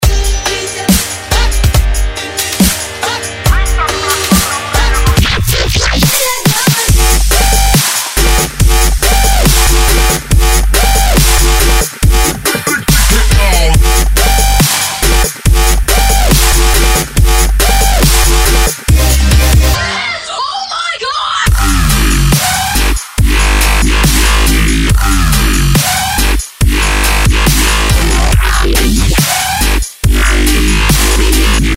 жесткие
женский голос
dance
Electronic
EDM
пугающие
Bass
Жесткий дабстеп с женским криком "Oh, my God" (О, мой Бог!)